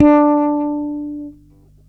40-D4.wav